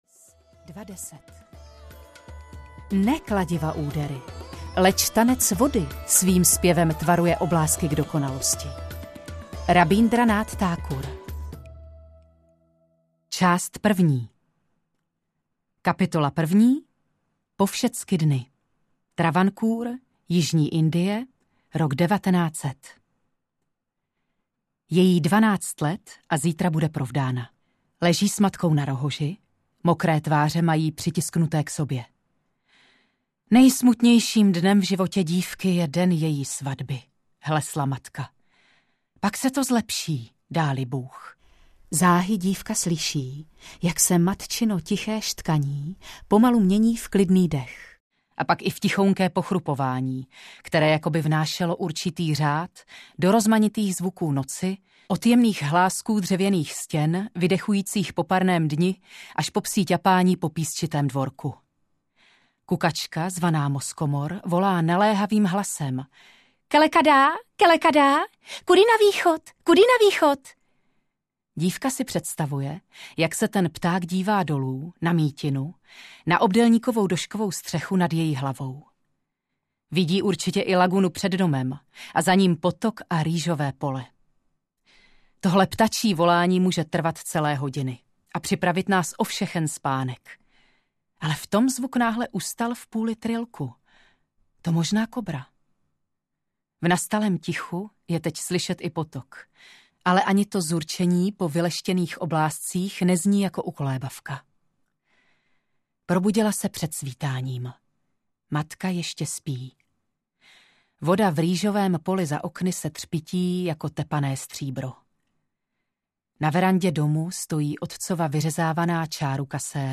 Úmluva s vodami audiokniha
Ukázka z knihy